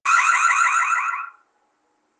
A Sirene RE-27C 20 NOBREAK é recomendada para alarmes contra incêndio, podendo ser instalada em ambientes internos ou externos, pois possui total proteção contra pó e chuva. A corneta é produzida em alumínio fundido, o que evita distorções sonoras.
SOM SIRENE ALTA POTENCIA RENGLAN